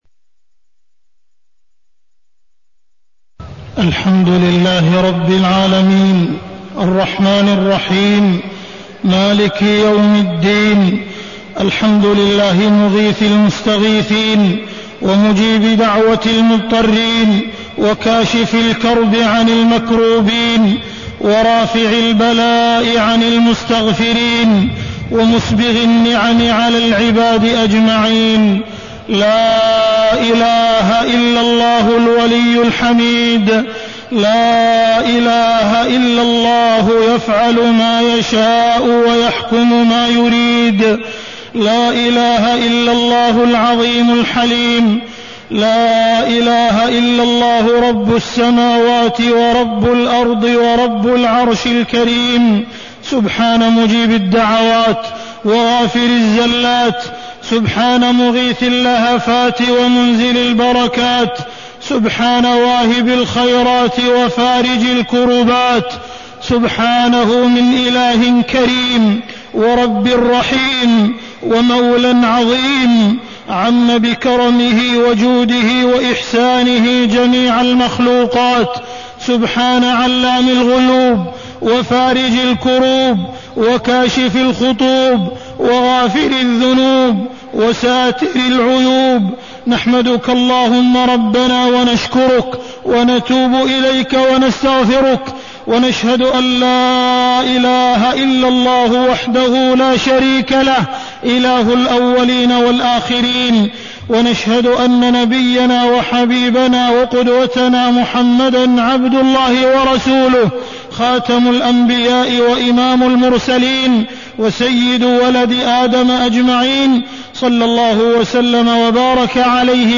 تاريخ النشر ٢ ذو الحجة ١٤٢٢ هـ المكان: المسجد الحرام الشيخ: معالي الشيخ أ.د. عبدالرحمن بن عبدالعزيز السديس معالي الشيخ أ.د. عبدالرحمن بن عبدالعزيز السديس تحقيق العبودية